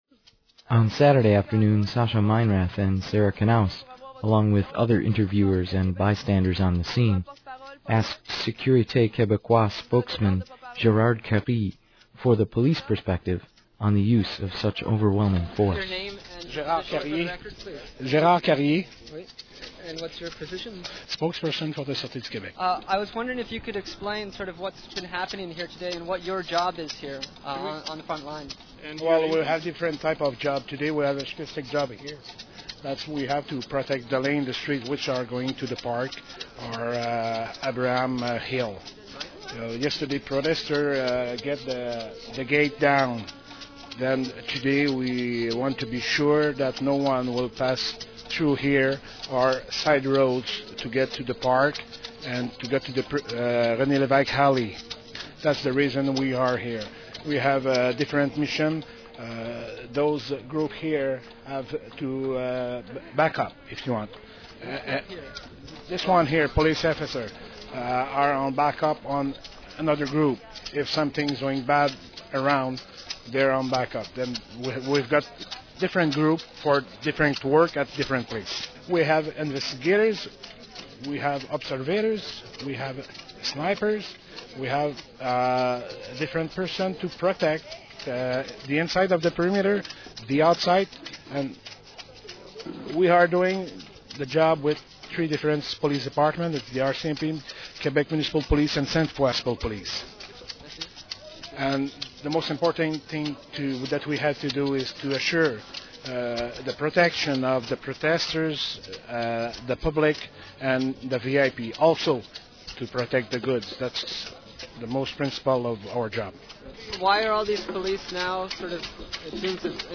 u-c_imc_ftaa_03police.mp3 (814 k)
Just days after arriving home from Quebec City and the protests surrounding the Summit of the Americas, Urbana-Champaign Independent Media Center journalists compiled a half-hour document of their journey and experiences on the streets of Quebec City. Originally aired April 30 on community radio WEFT, during the IMC Newshour.